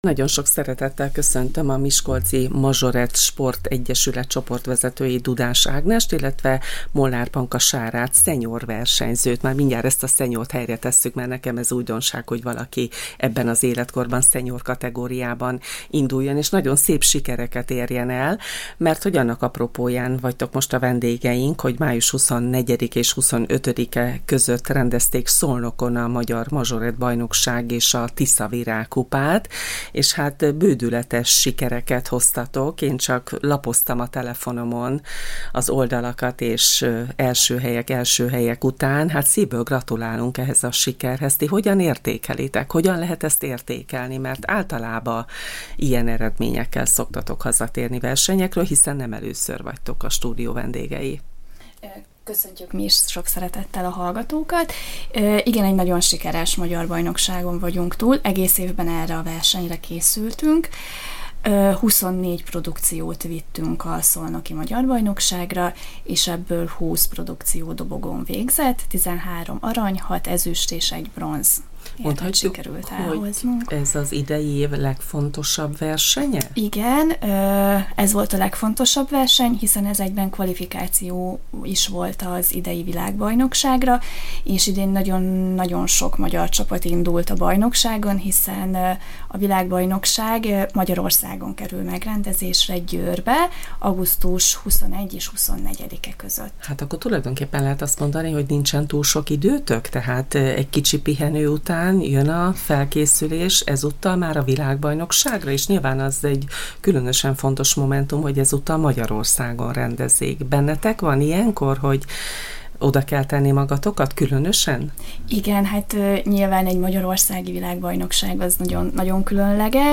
a Csillagpont Rádió magazinműsorában. Megtudtuk, a szolnoki verseny egyben kvalifikáció is volt az augusztusi győri világbajnokságra.